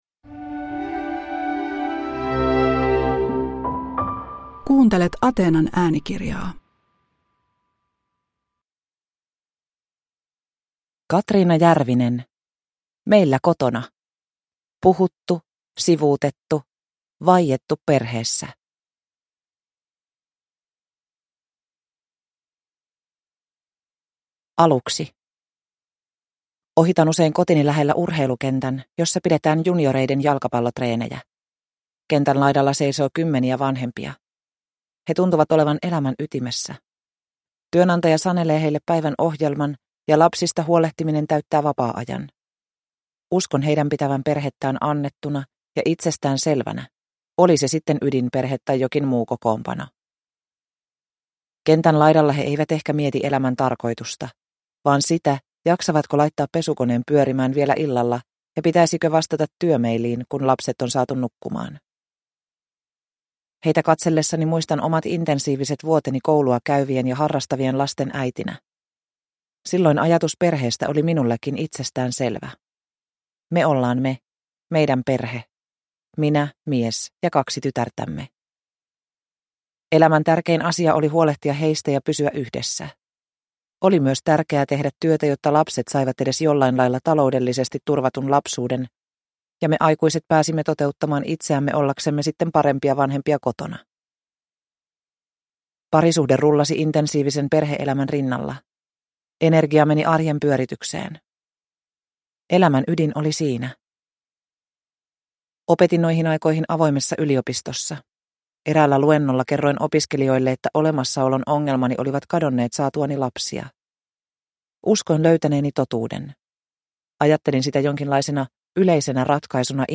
Meillä kotona (ljudbok) av Katriina Järvinen